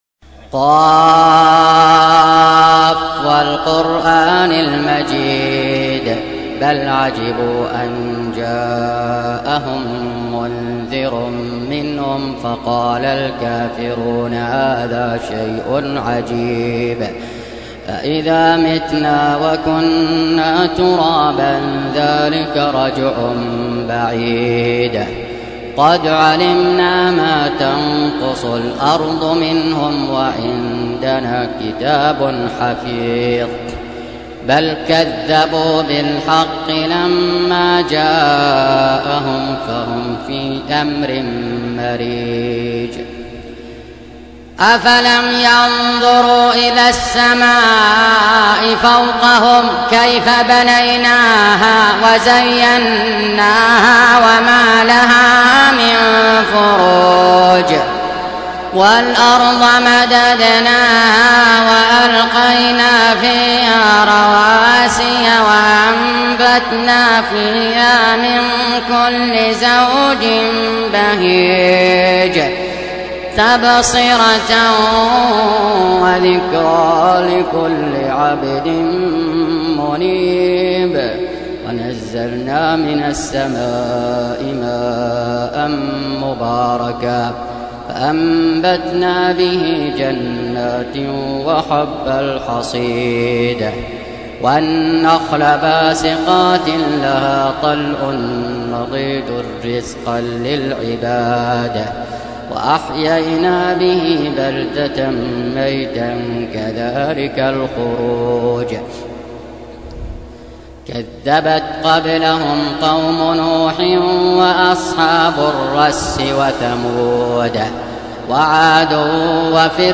Surah Repeating تكرار السورة Download Surah حمّل السورة Reciting Murattalah Audio for 50. Surah Q�f. سورة ق N.B *Surah Includes Al-Basmalah Reciters Sequents تتابع التلاوات Reciters Repeats تكرار التلاوات